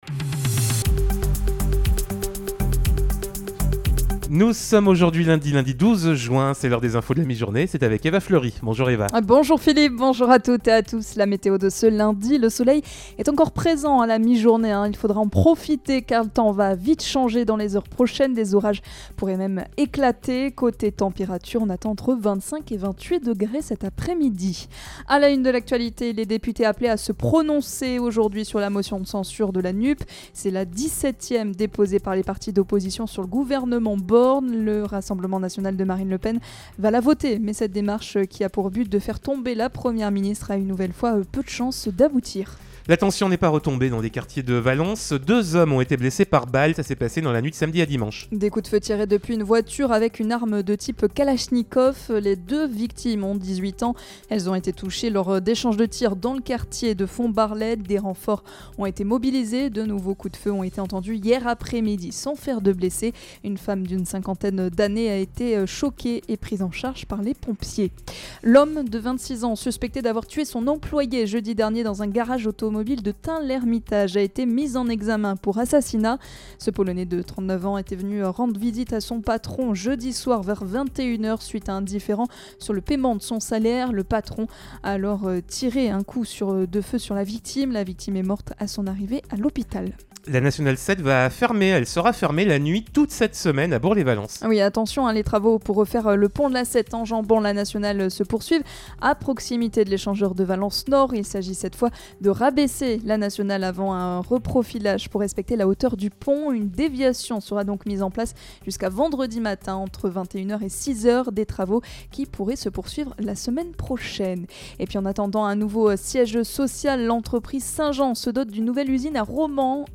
Lundi 12 juin : Le journal de 12h